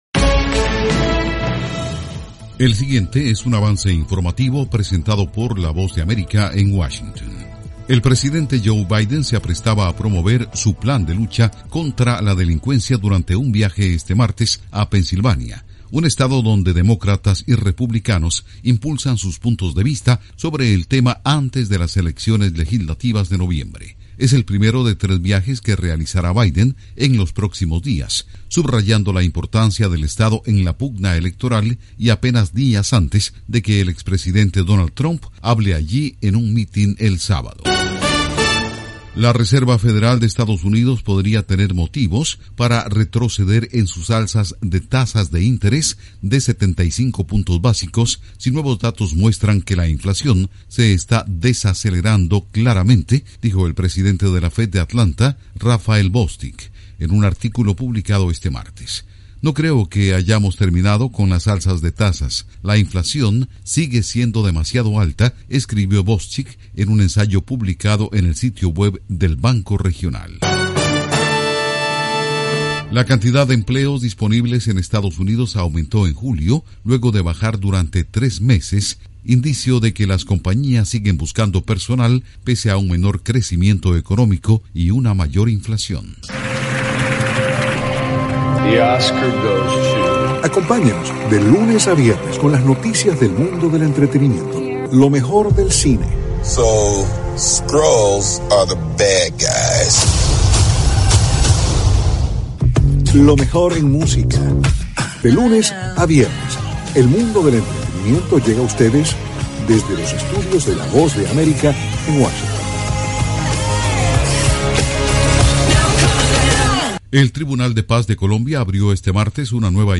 Avance Informativo 2:00 PM
El siguiente es un avance informativo presentado por la Voz de América en Washington.